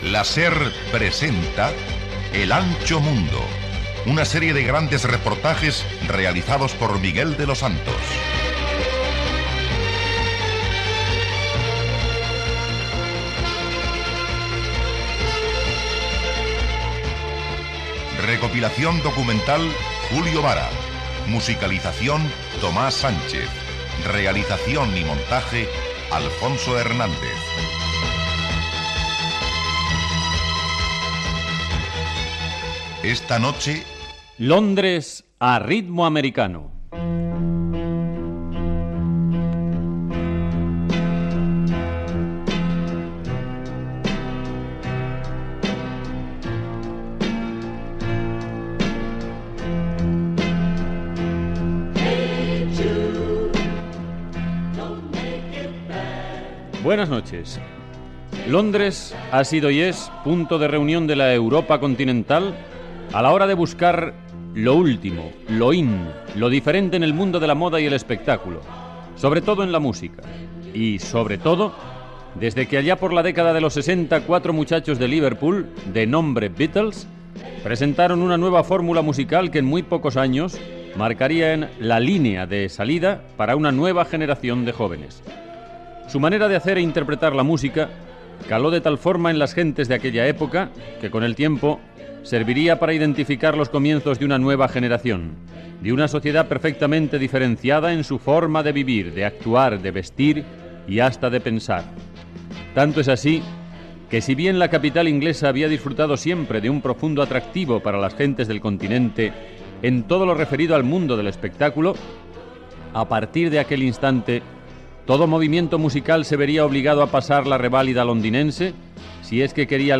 Careta del programa i espai dedicat a la ciutat de Londres
Entreteniment